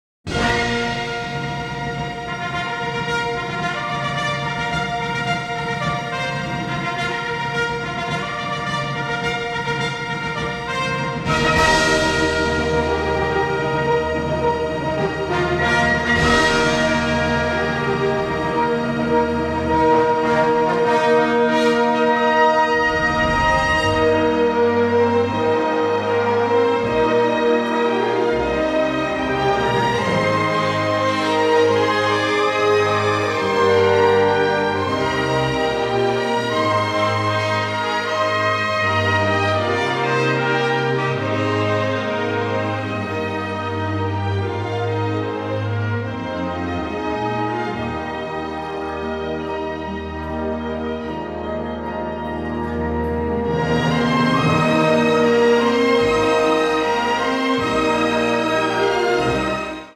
orchestral performances of film music